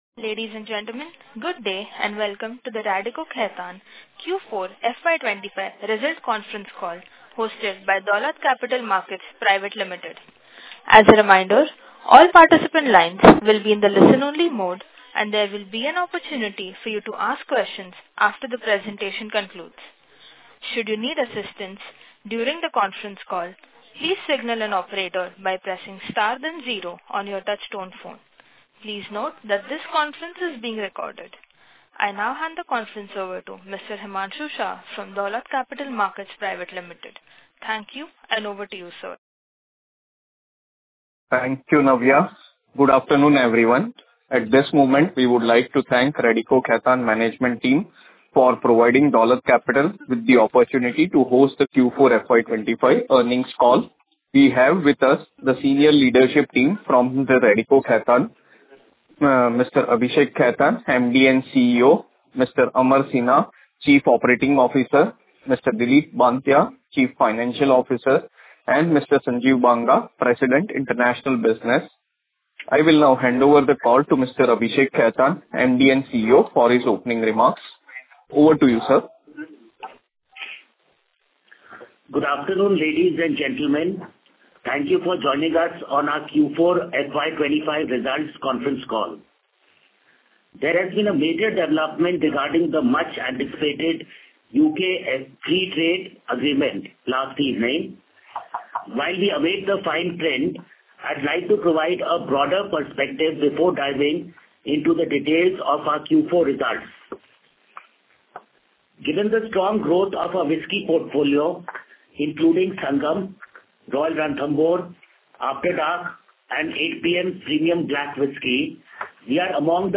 Q4-FY2025-Concall-Recording.mp3